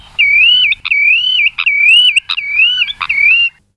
Tringa erythropus